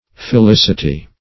Felicity \Fe*lic"i*ty\, n.; pl.